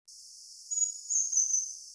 35-3溪頭2011黃胸青鶲s2.mp3
黃胸青鶲 Ficedula hyperythra innexa
錄音地點 南投縣 鹿谷鄉 溪頭
NA 錄音環境 森林 發聲個體 行為描述 鳥叫 錄音器材 錄音: 廠牌 Denon Portable IC Recorder 型號 DN-F20R 收音: 廠牌 Sennheiser 型號 ME 67 標籤/關鍵字 備註說明 MP3檔案 35-3溪頭2011黃胸青鶲s2.mp3